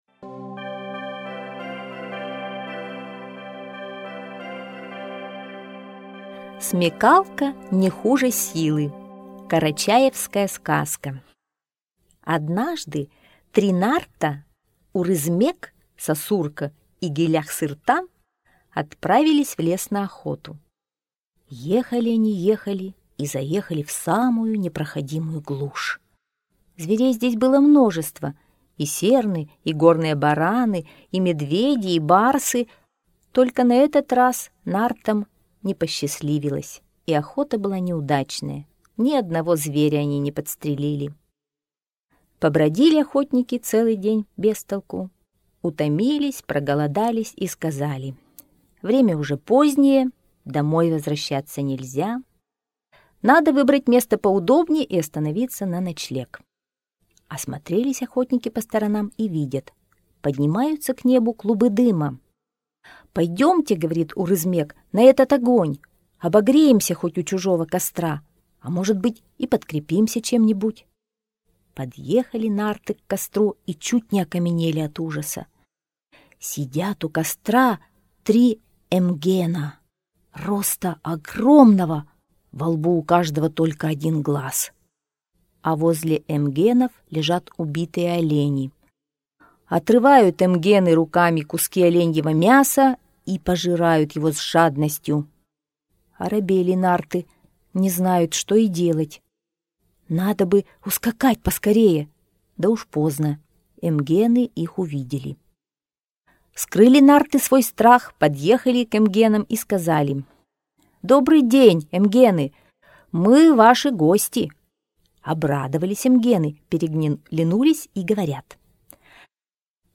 Смекалка не хуже силы - карачаевская аудиосказка - слушать онлайн